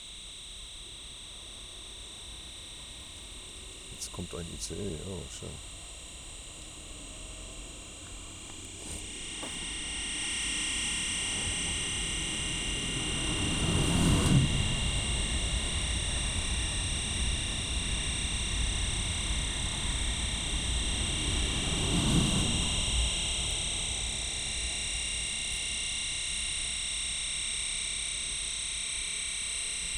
Abb. 03-04-20: Bahnstrecke München-Berlin, nördlich vom Bahnhof Forchheim.
Die Fahrdrähte befinden sich unter einer Fußgängerbrücke.
Abb. 03-04-21: bei Marke 5 sec.: "gleich kommt ein ICE", Mikrofonsignal und Ausgang der Aufnehmerspule sind überlagert.
Bereits vor der Ankunft des Zuges ist das Signal im Bereich oberhalb 2 kHz stark dauerhaft verunreinigt.